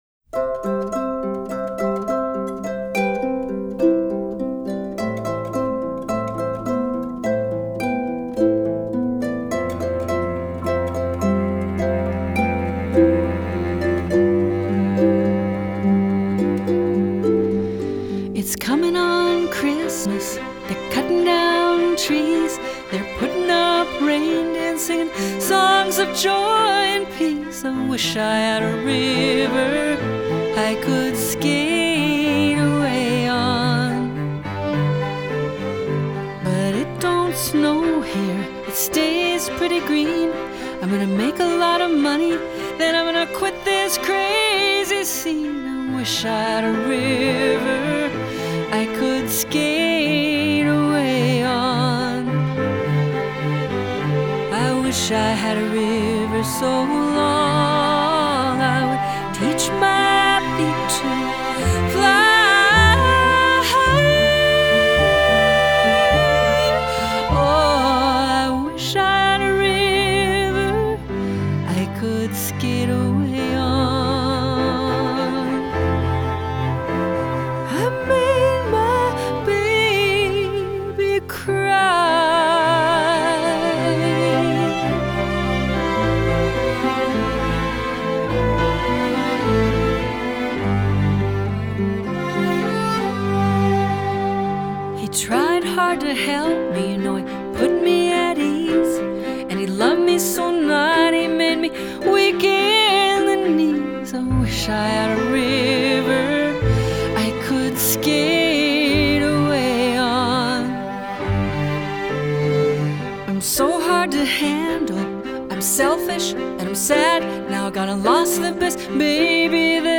a very straightforward version of the song
with a lovely chamber music-style arrangement.